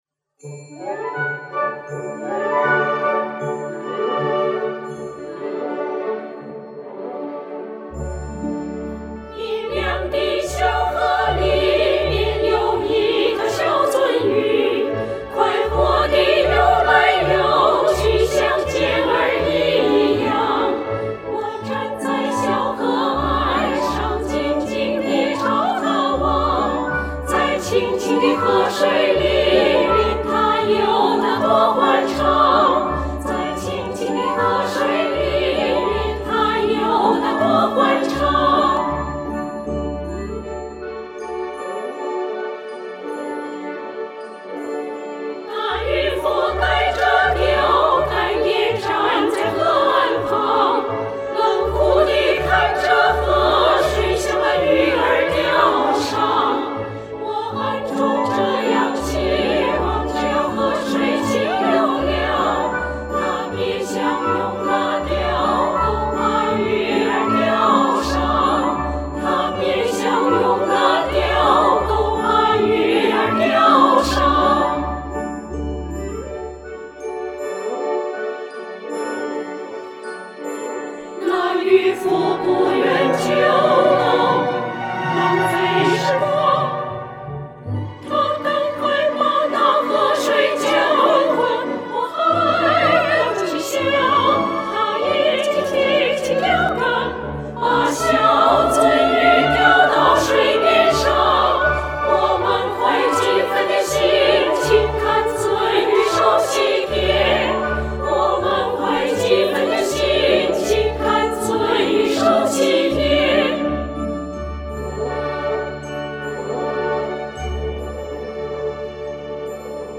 女声小合唱【鳟鱼】- 舒伯特曲，11位歌友演唱